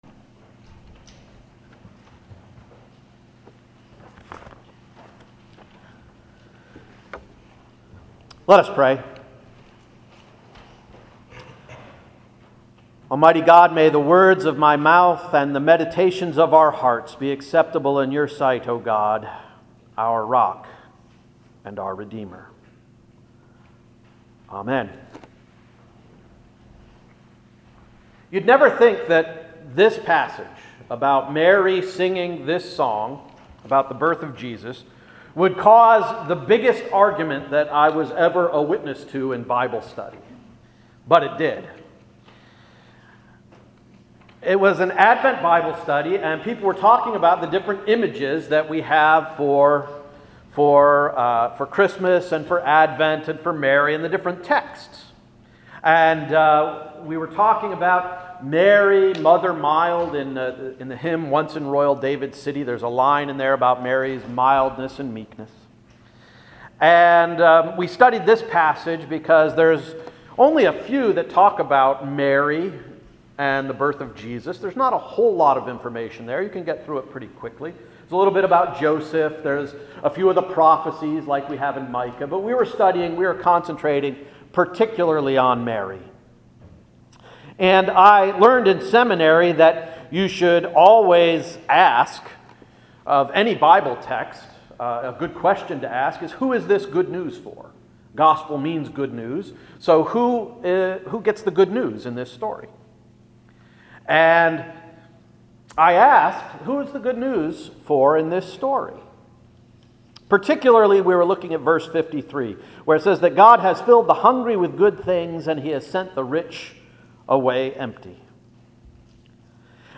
December 11, 2016 Sermon — What did Mary know ( & we don’t)?